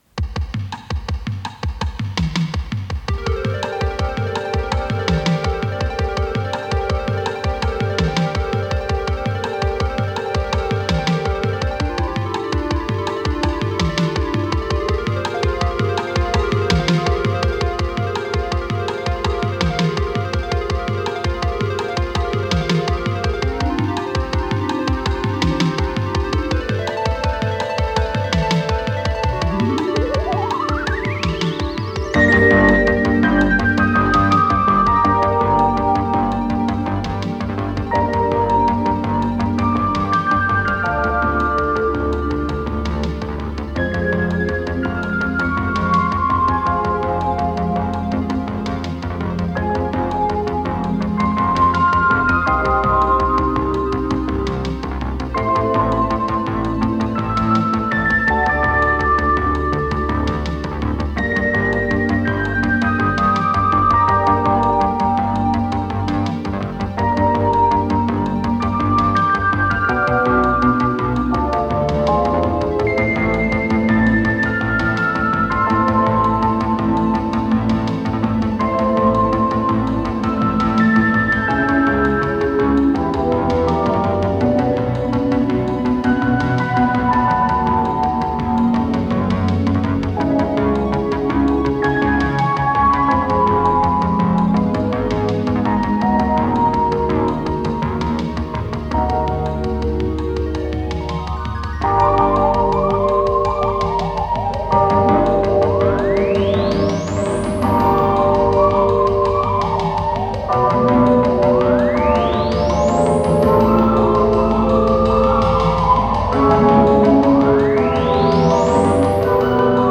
с профессиональной магнитной ленты
синтезатор
ВариантДубль моно